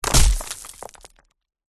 Звуки ударов предметов
Тяжелый предмет обрушивается на землю с глухим гулом, взметая в воздух грязь, осколки камней и мусор.